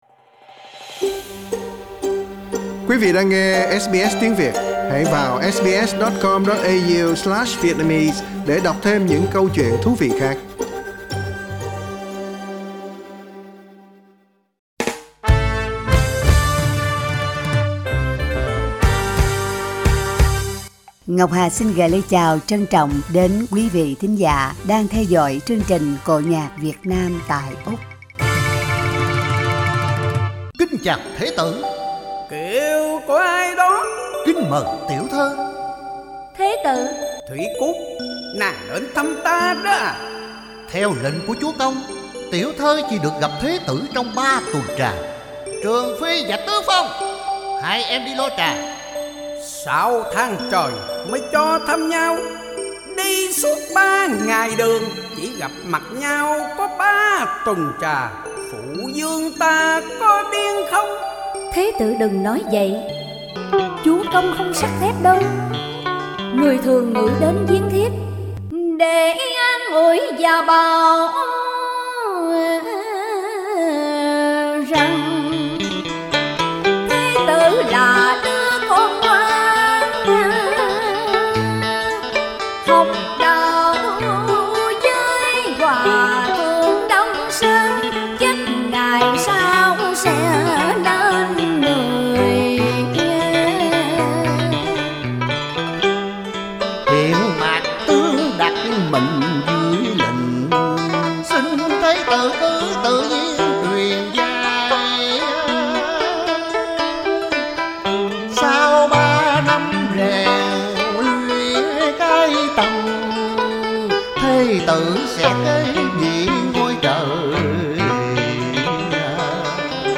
làn điệu dân ca cải lương
tuồng cổ